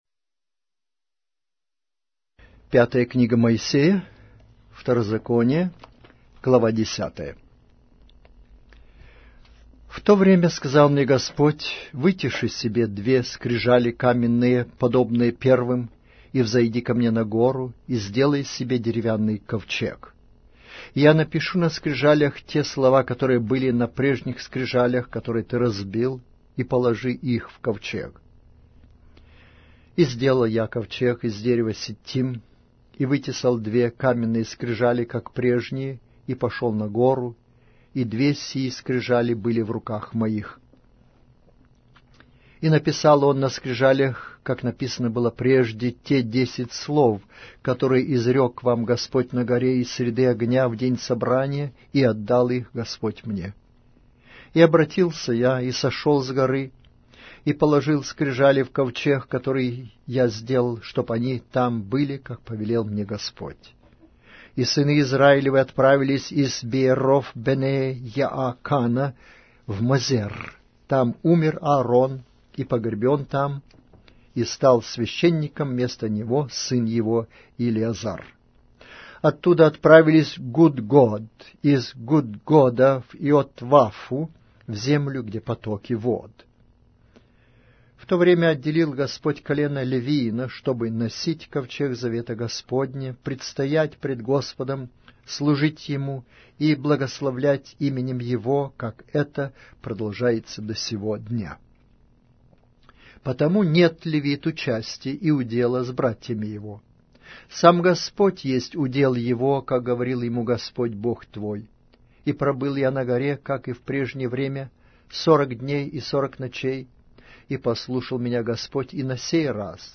Аудиокнига: Книга 5-я Моисея. Второзаконие